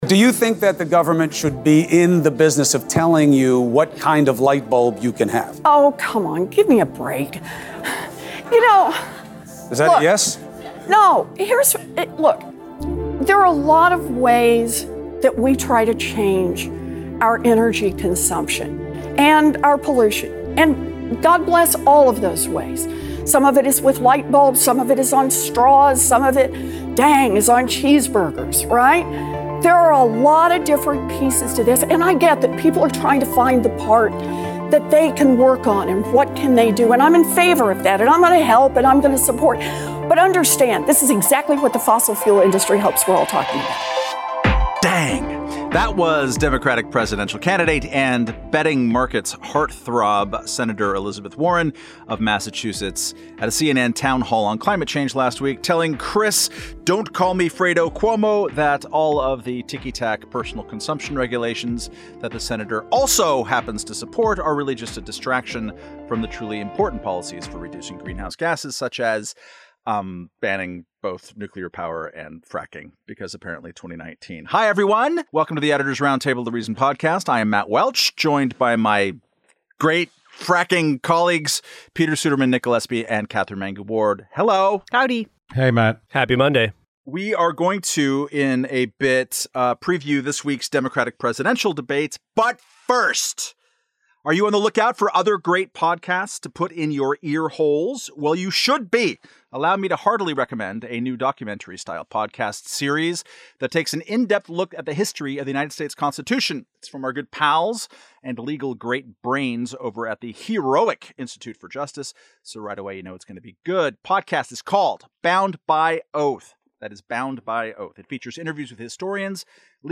Editors' Roundtable edition of the Reason Podcast